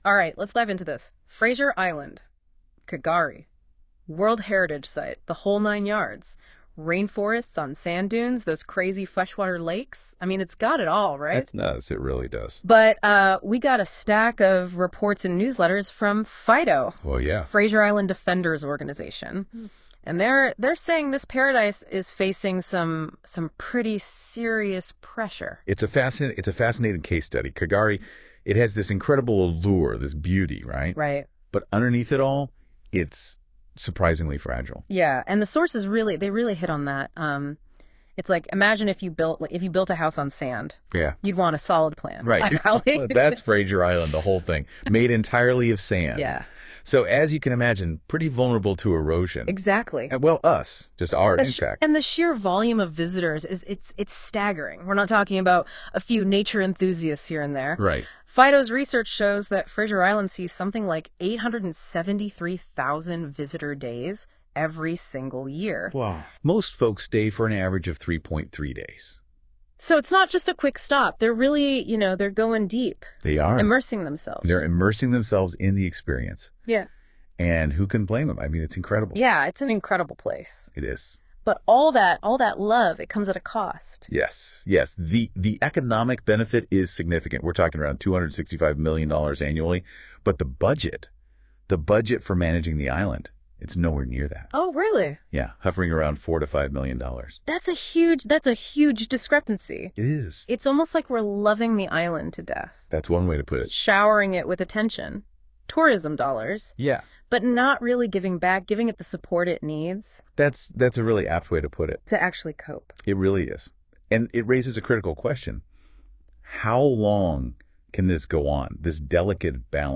Introduction to K’gari – Audio Conversation Summarising K’gari
Using some current AI technology from Google, specifically NotebookLM, we have been able to generate an Audio summary so that more people can learn about K’gari.
Please excuse the American accents and miss-pronunciation of K’gari, the AI isn’t clever enough to do Aussie accents and traditional languages yet.